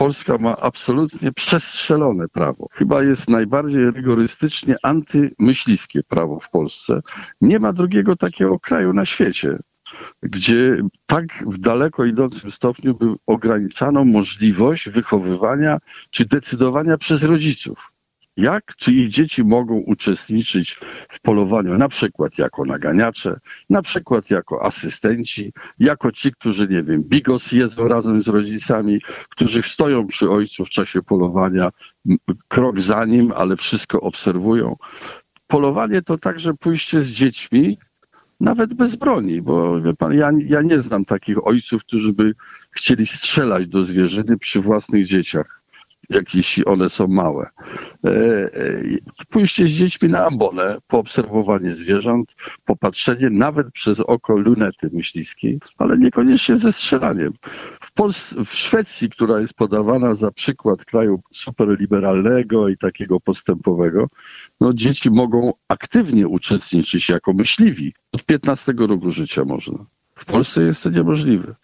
Za możliwością udziału dzieci w polowaniach jest Bronisław Komorowski, były prezydent RP. Swoje zdanie przedstawił w audycji „Gość Radia 5”.